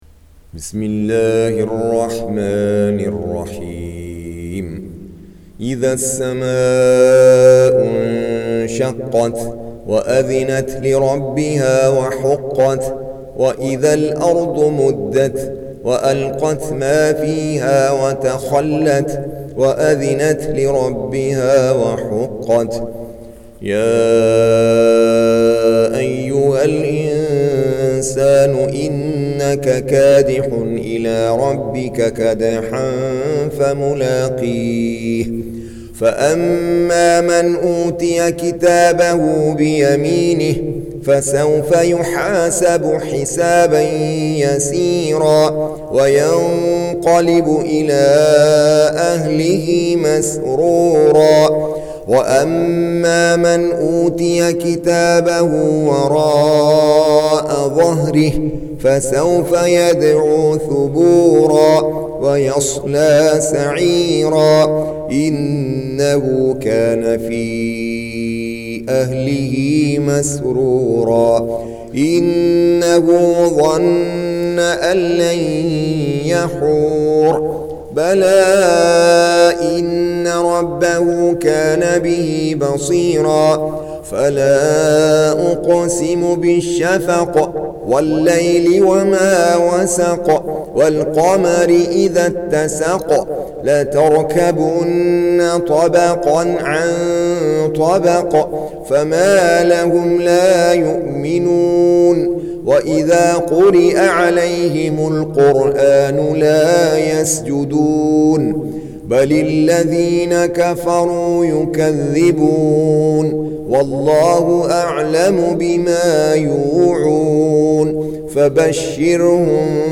Surah Sequence تتابع السورة Download Surah حمّل السورة Reciting Murattalah Audio for 84. Surah Al-Inshiq�q سورة الإنشقاق N.B *Surah Includes Al-Basmalah Reciters Sequents تتابع التلاوات Reciters Repeats تكرار التلاوات